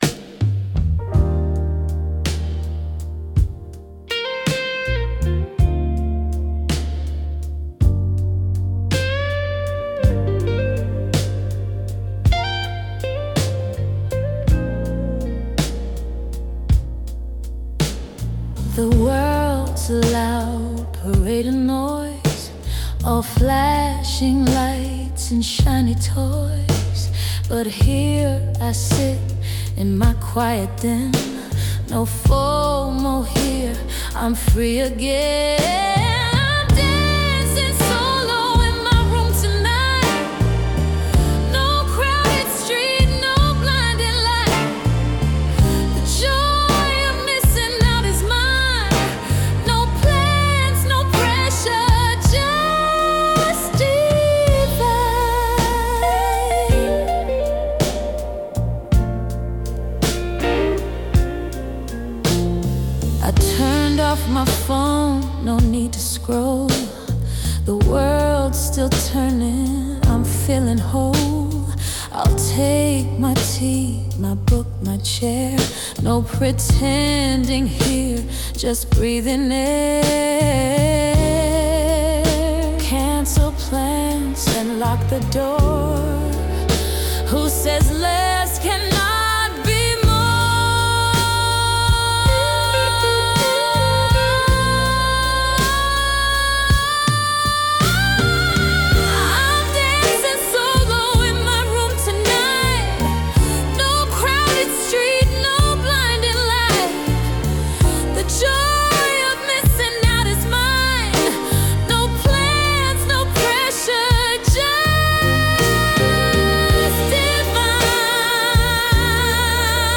'Joy of Missing Out' - taki spokojny utwór zrobiony w większości przy pomocy AI.